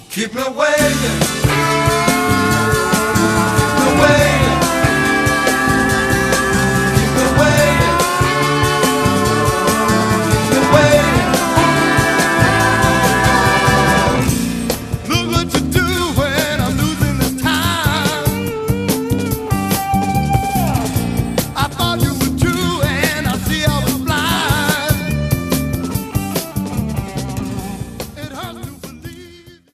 the horn section